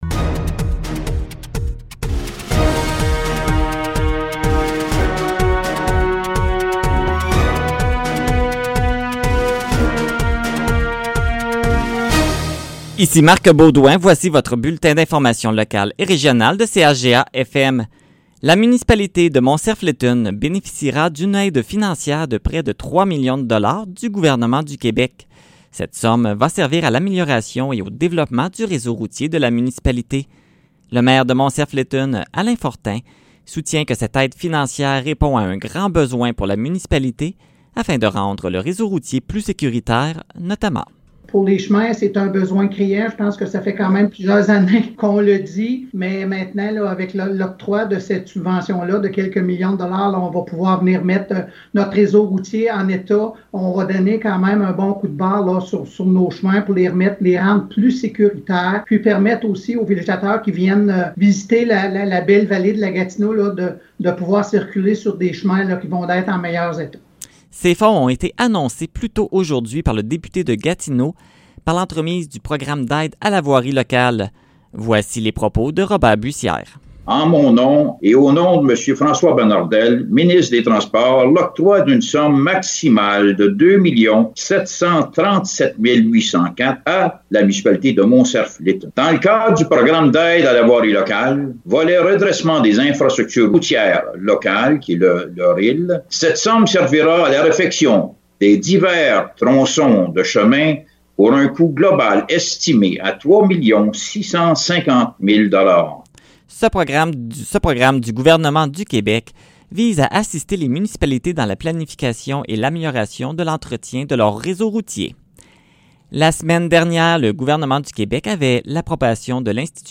Nouvelles locales - 20 octobre 2020 - 15 h